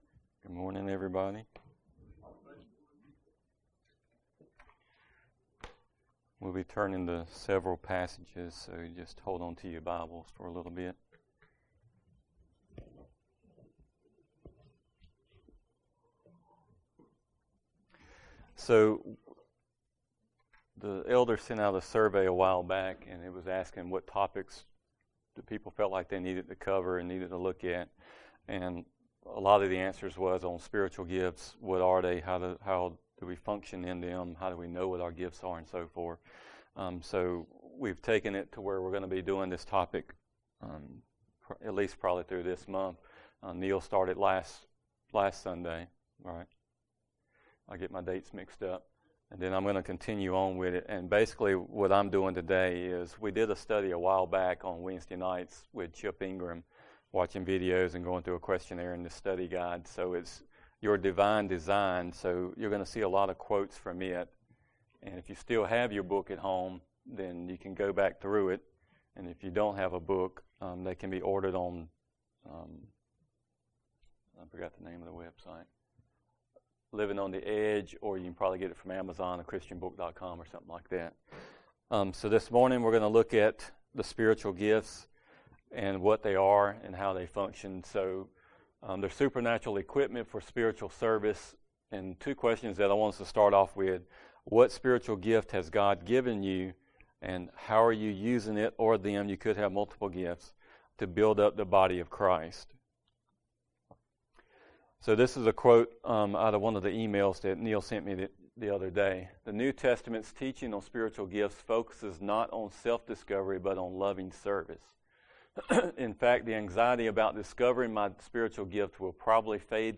Passage: 1 Corinthians 12:4-7 Service Type: Sunday Morning Related Download Files Notes Topics